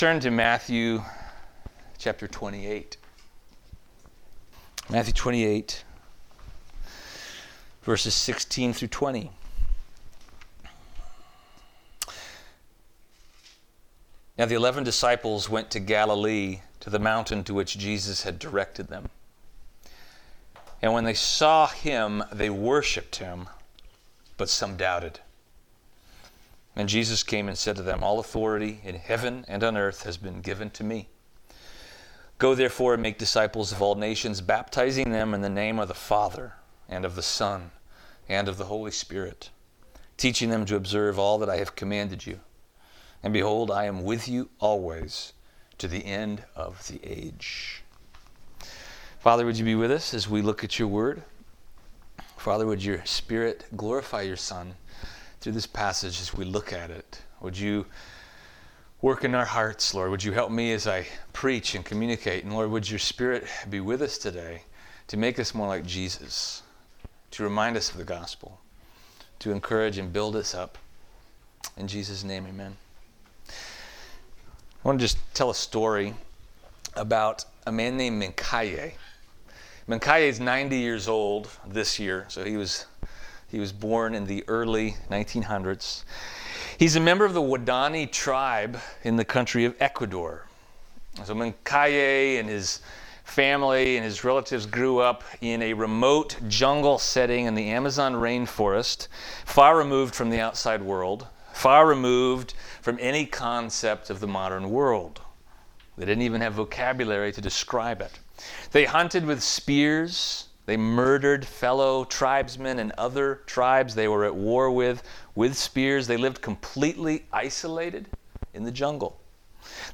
Matthew 28:16-20 Service Type: Sunday